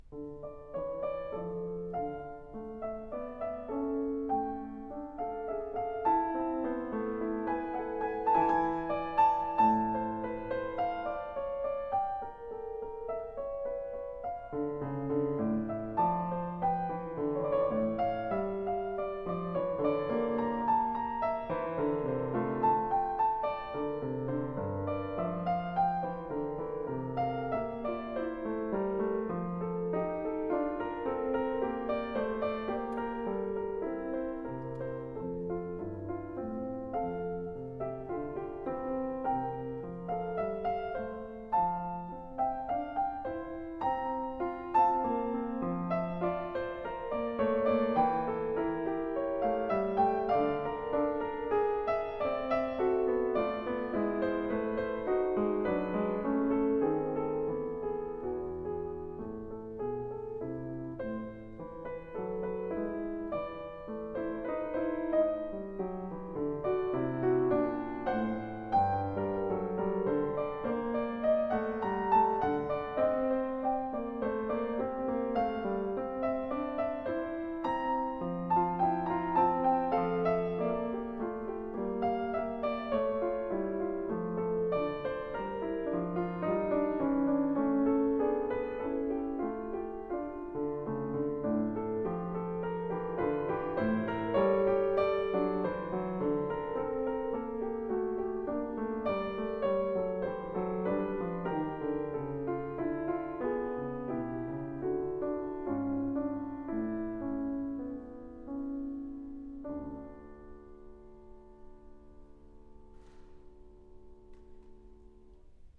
Piano version
Classical Piano